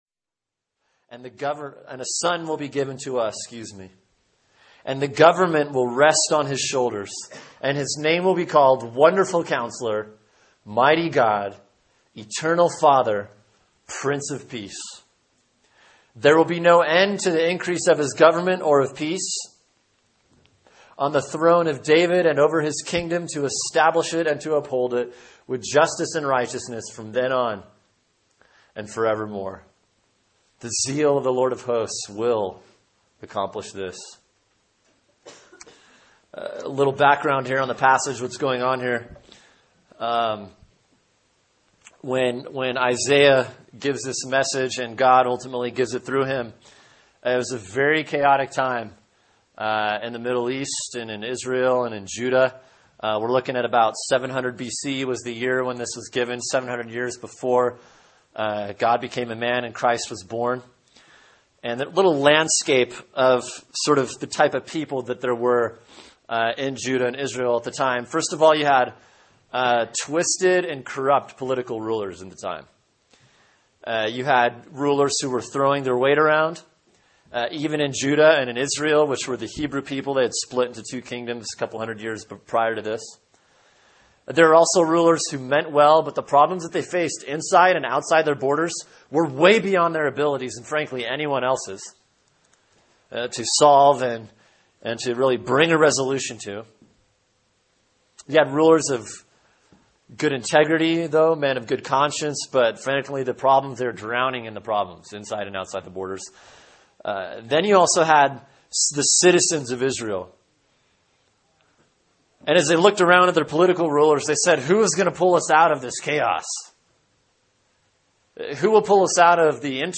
Sermon: Isaiah 9:1-7 “Real Hope” | Cornerstone Church - Jackson Hole